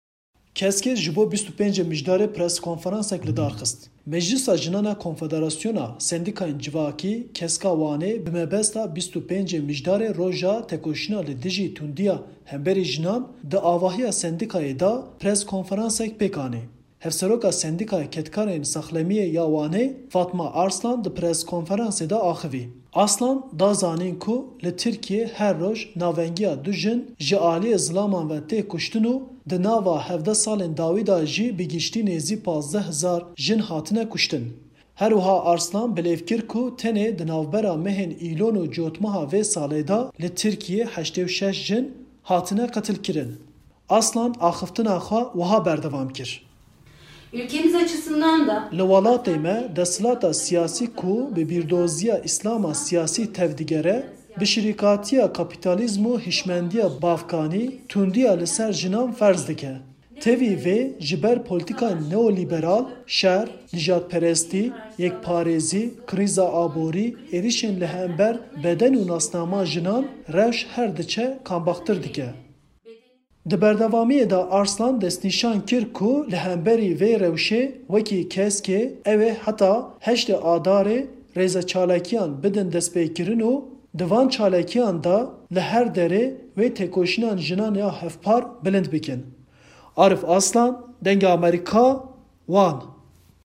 Meclîsa Jinan ya Konfederasyona Sendîkayên Civakî (KESK) ya Wanê li bîranîna ‘25ê Mijdarê roja têkoşîna li dîjî tundîya li hemberî jinan di avahîya sendîkayê de preskonferansek pêkanî.